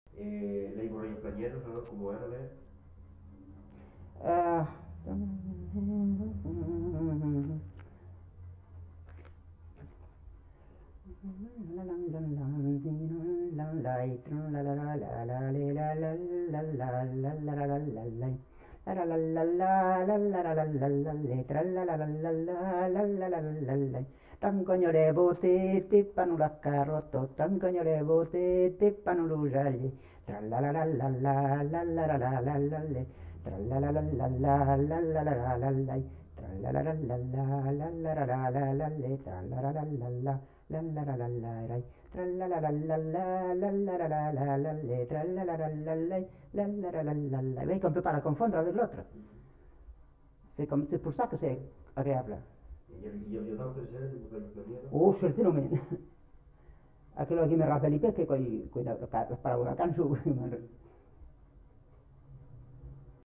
Aire culturelle : Haut-Agenais
Lieu : Castillonnès
Genre : chant
Type de voix : voix de femme
Production du son : chanté ; fredonné
Danse : bourrée
Contextualisation de l'item : bourrée planière